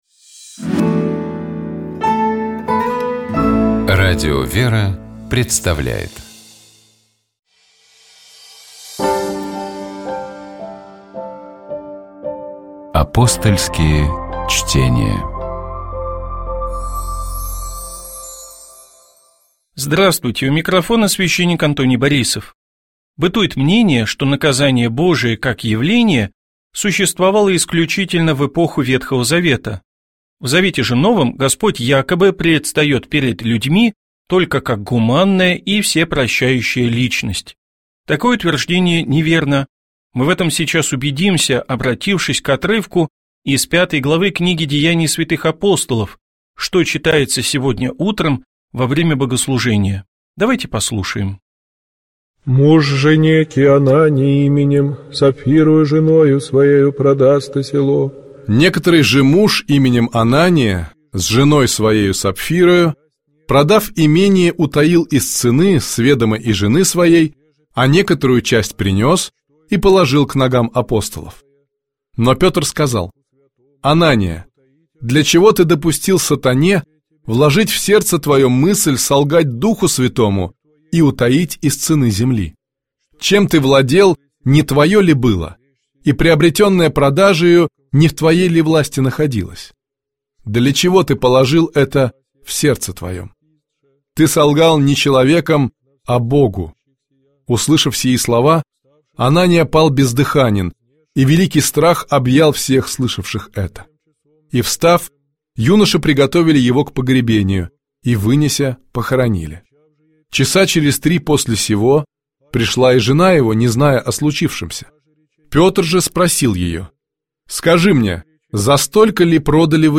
В программе Апостольские чтения можно услышать толкование из новозаветного чтения, которое звучит в этот день в Православных храмах.